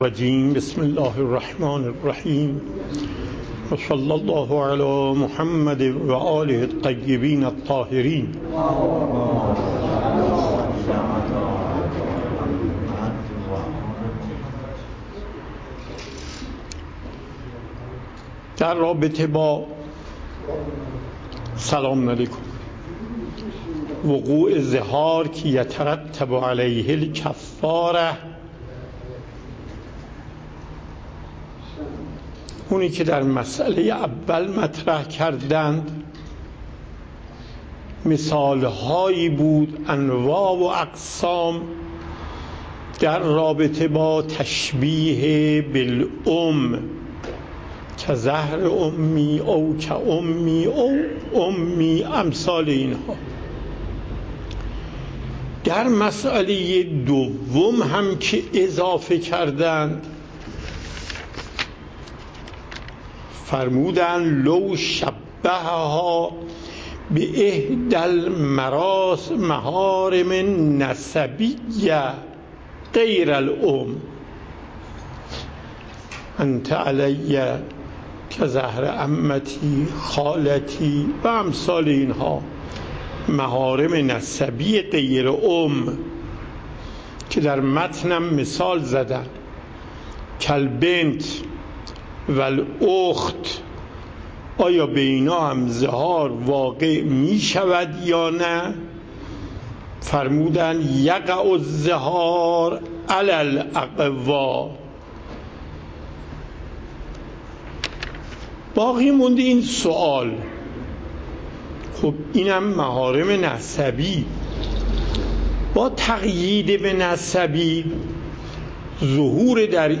پخش زنده صوت درس + دریافت صوت و تقریر درس